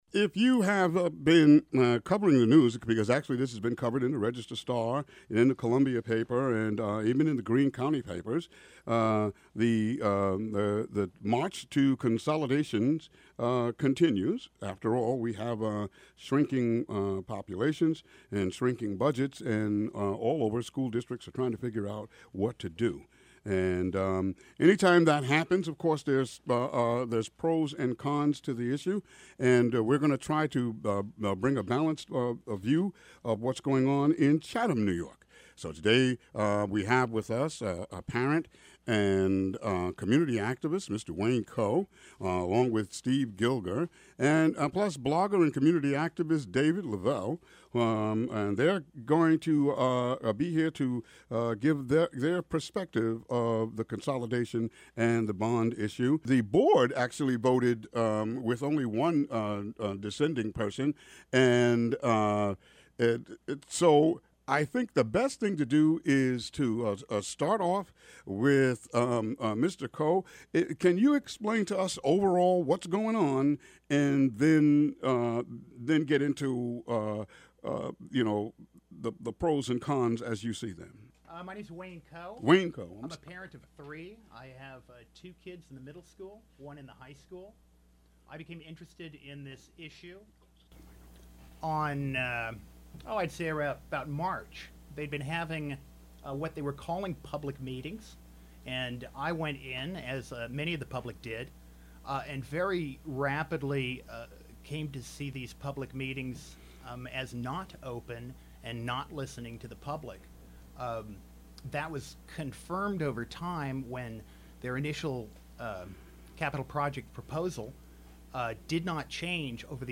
WGXC Morning Show Contributions from many WGXC programmers.